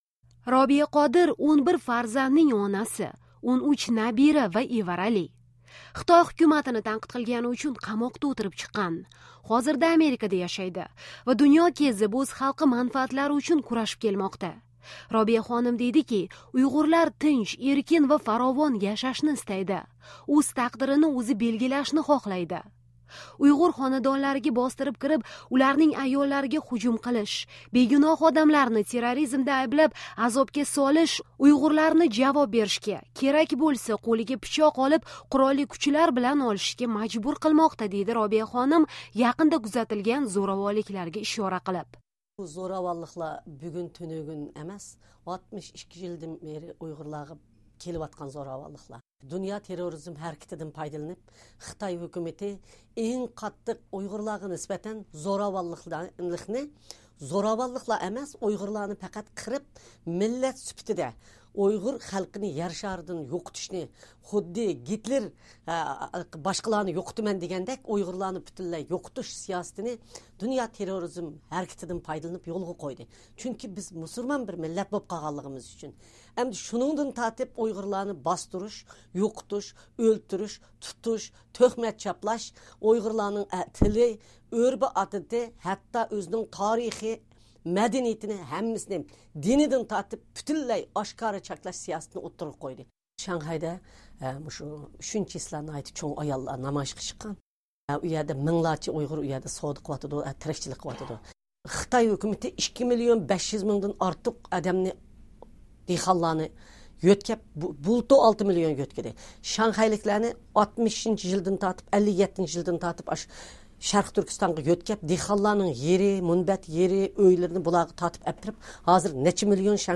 Jahon Uygurlari yetakchisi Robiya Qodir bilan suhbat - Navbahor Imamova